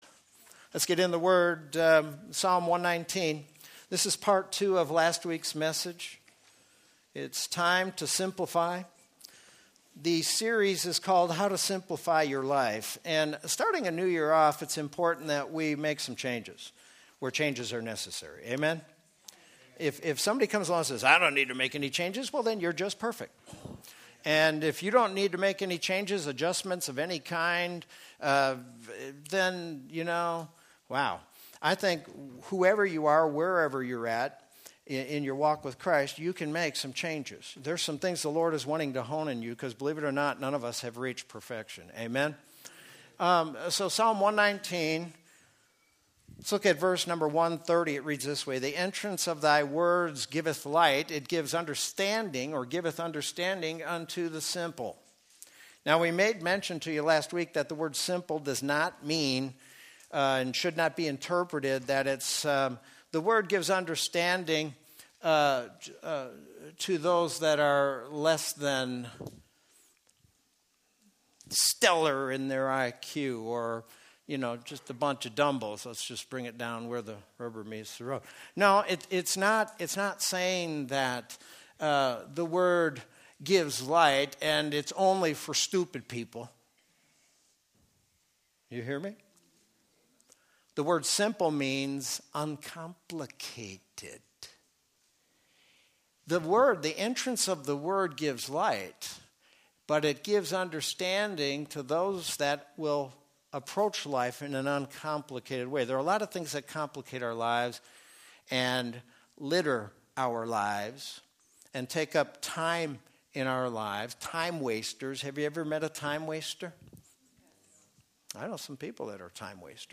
Sermon from January 12, 2020.